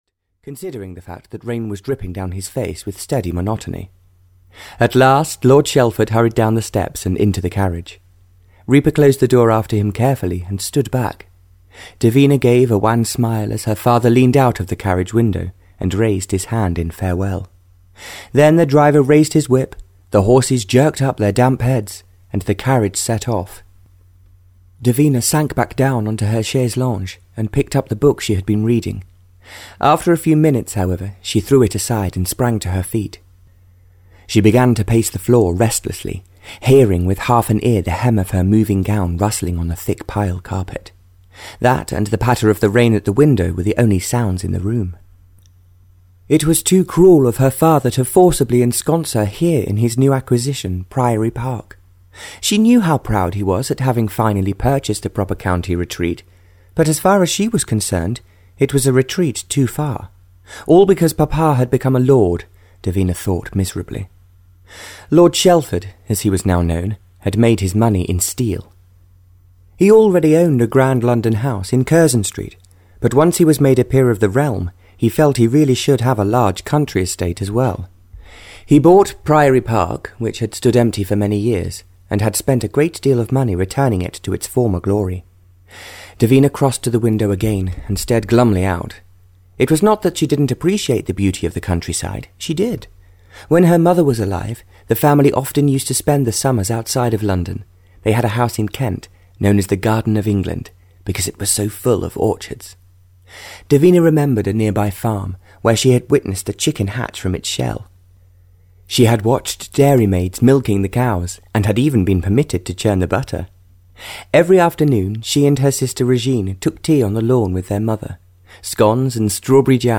Theirs to Eternity (Barbara Cartland’s Pink Collection 15) (EN) audiokniha
Ukázka z knihy